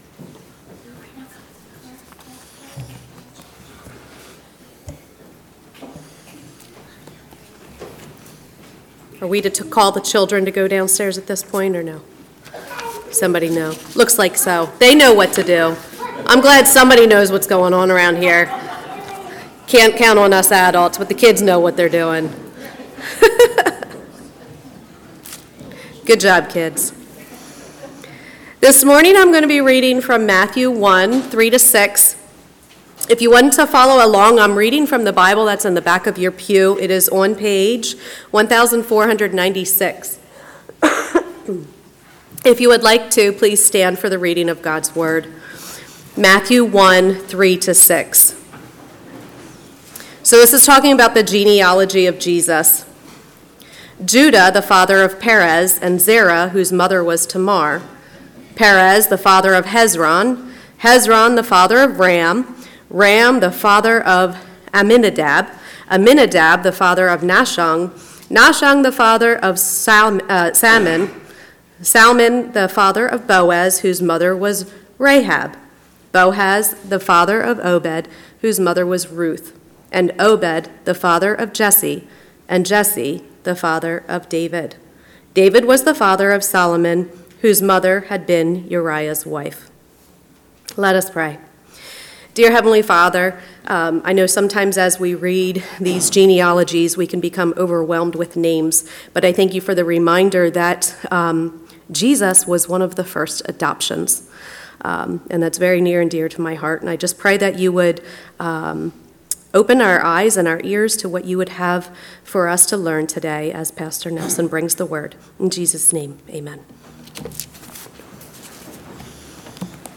A message from the series "December 2025."